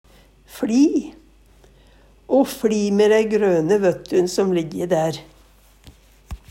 DIALEKTORD PÅ NORMERT NORSK fLi gje, overrekkje Infinitiv Presens Preteritum Perfektum fLi fLir fLidde fLidd Eksempel på bruk O fLi me dei grøne vøttun som ligg der.